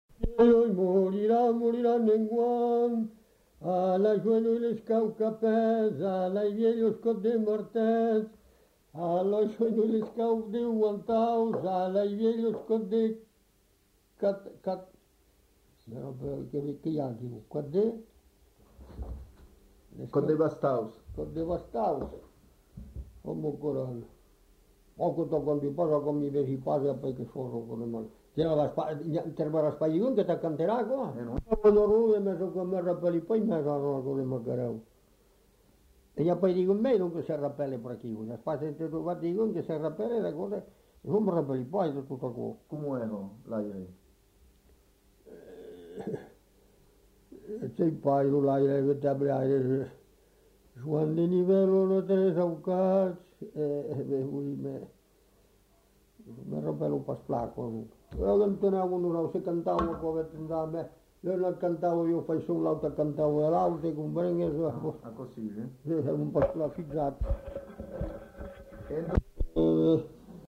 Lieu : Cazaux-Savès
Genre : chant
Effectif : 1
Type de voix : voix d'homme
Production du son : chanté